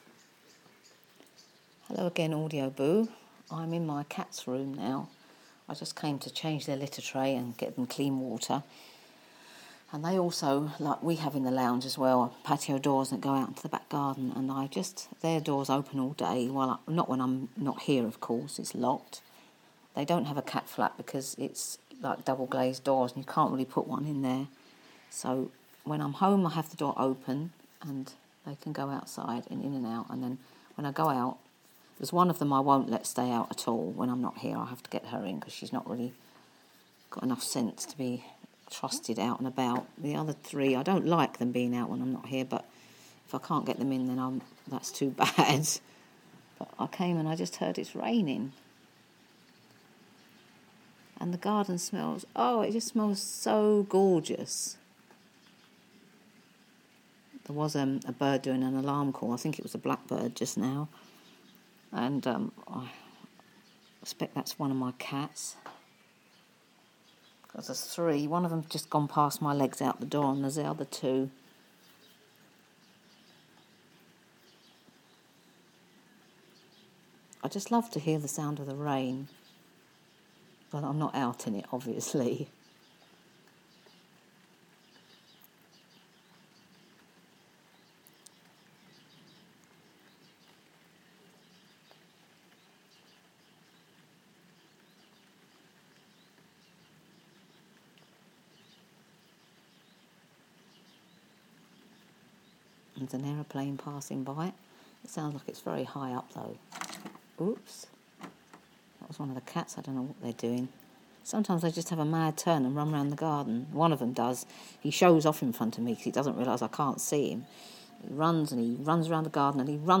Rain and Bird Sounds